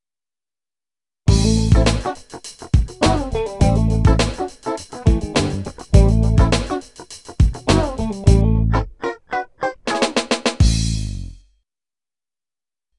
funk.wav